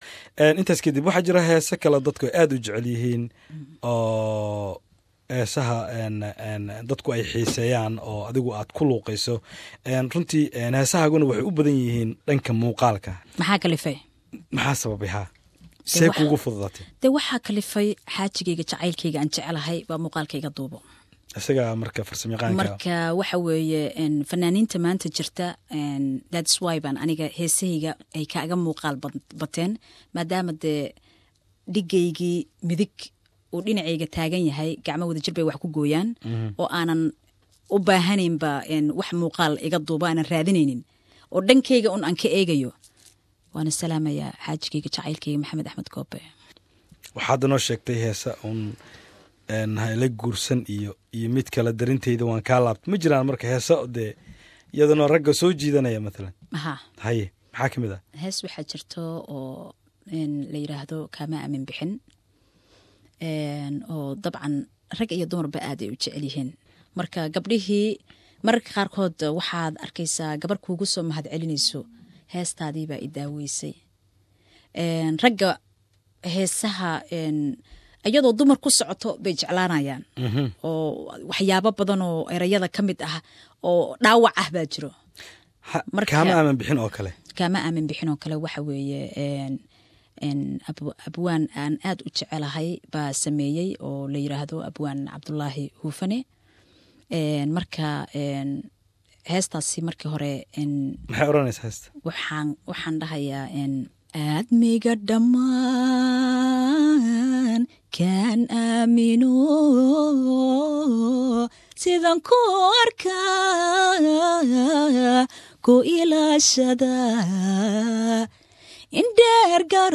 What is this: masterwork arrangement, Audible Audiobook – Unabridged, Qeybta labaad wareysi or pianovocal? Qeybta labaad wareysi